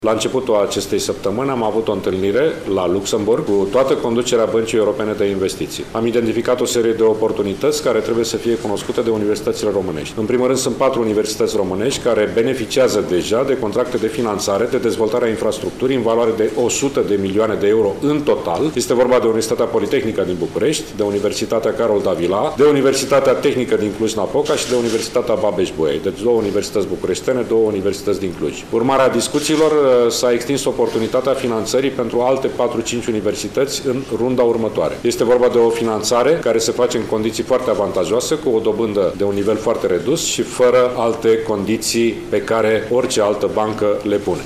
Banca Euorpeană de Investiții va suplimenta fondurile pentru încă 4-5 universități din România, a anunțat astăzi, la Iași, Ministrul Educației, Sorin Cîmpeanu.